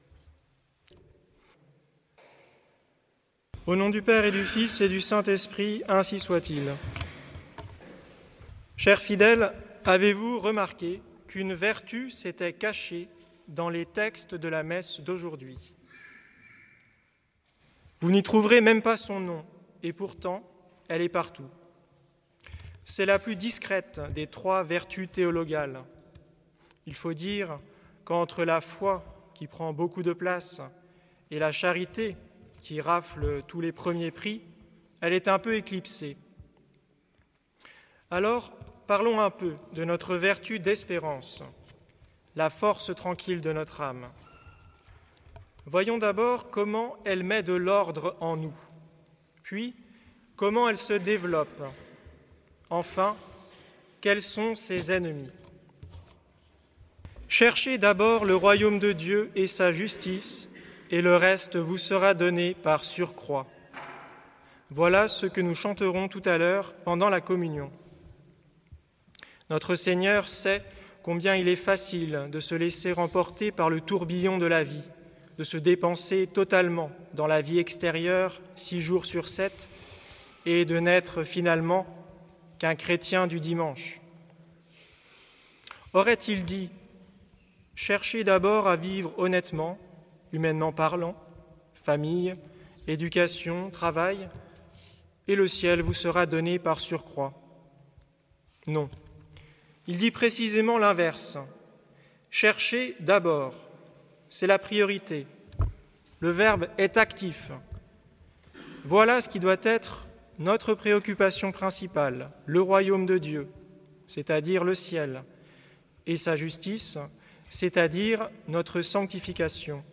Occasion: Neuvième dimanche après la Pentecôte
Type: Sermons